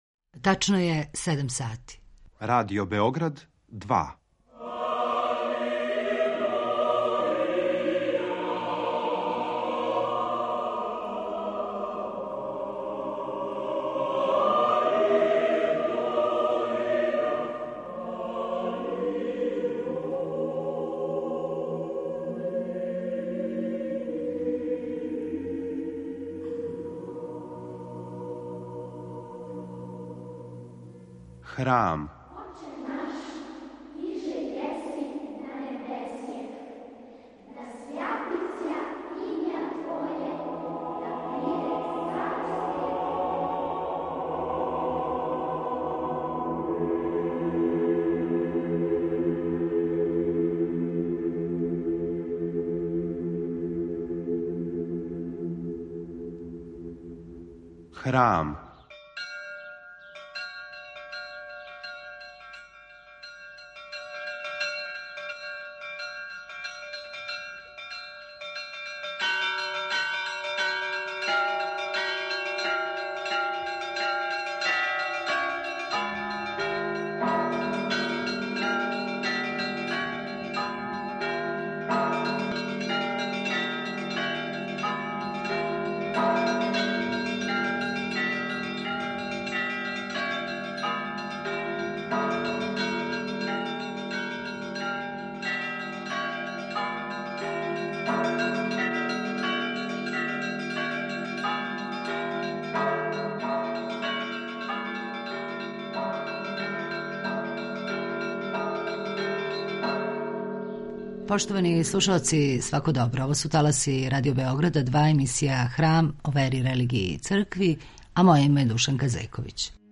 Гост Храма историчар и публициста